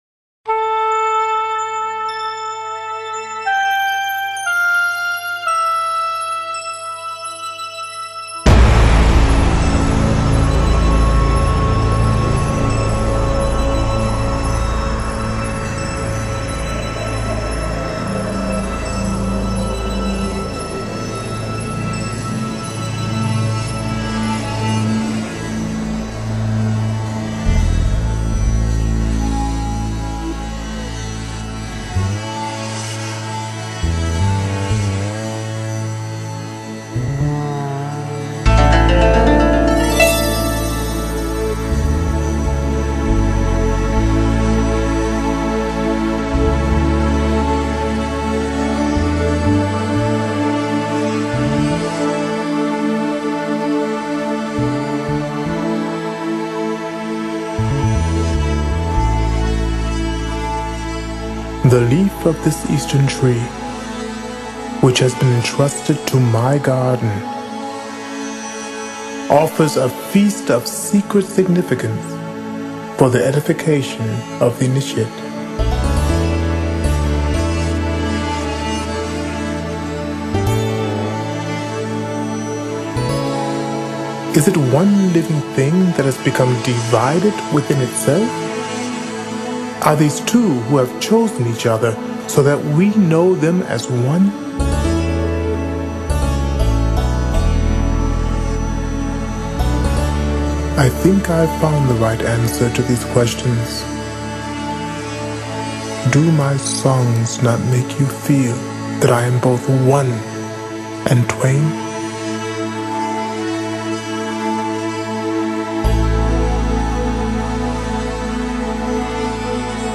那种恬静纯然的新世纪纯音乐，让我们聆听后，明白，这才是真正的新世纪音乐的标准和代表。